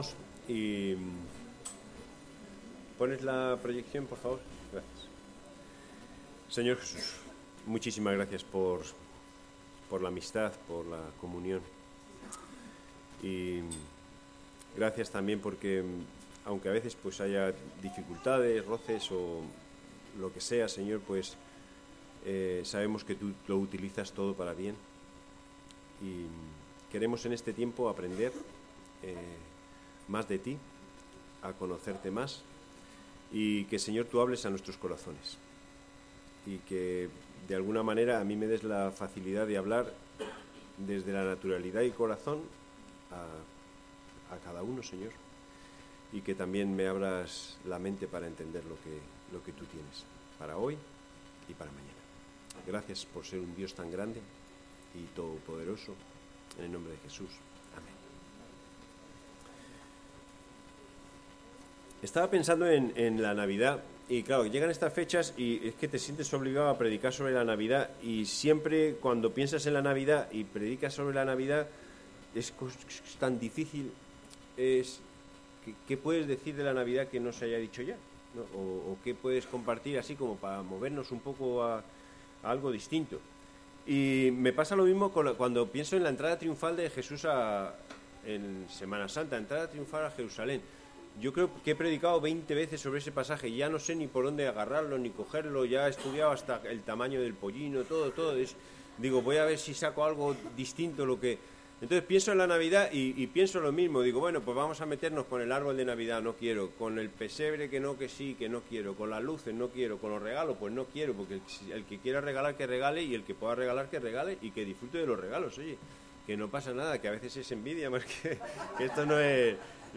*Por problemas técnicos en el programa de grabación solo se han podido grabar los primeros 15 minutos.